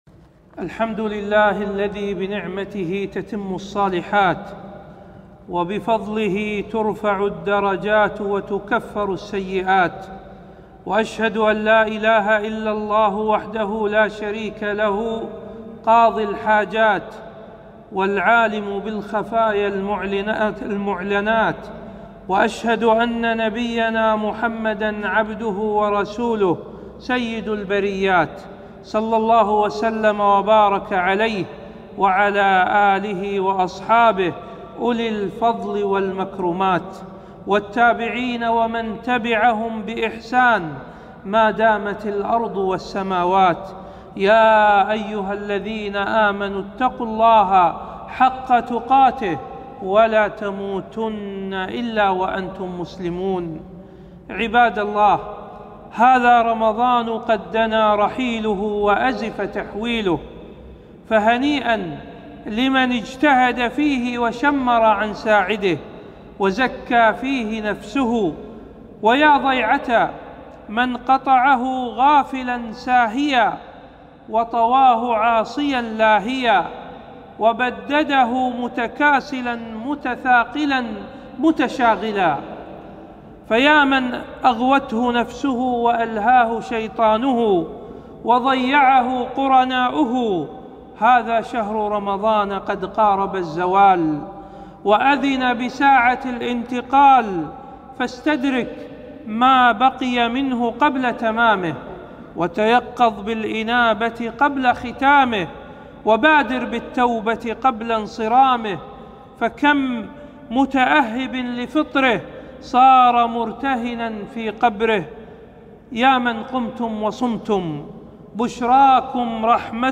خطبة - عبادات في نهاية رمضان